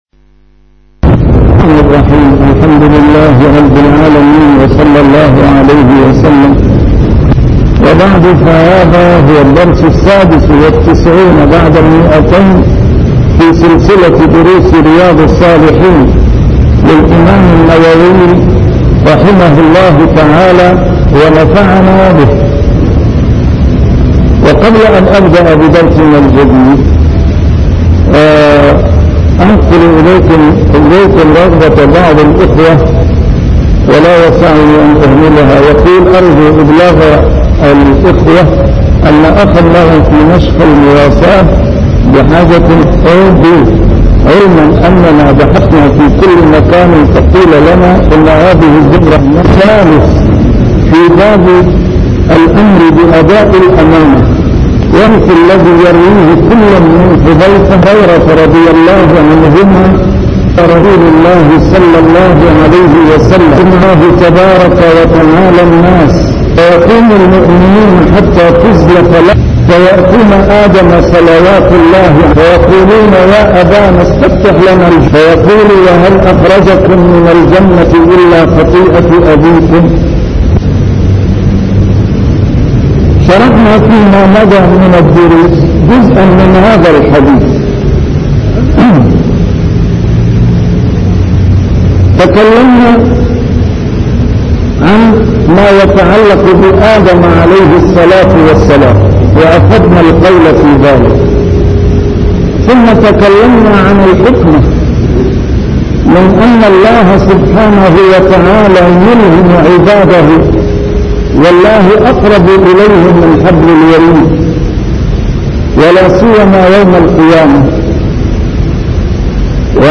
A MARTYR SCHOLAR: IMAM MUHAMMAD SAEED RAMADAN AL-BOUTI - الدروس العلمية - شرح كتاب رياض الصالحين - 296- شرح رياض الصالحين: الأمر بأداء الأمانة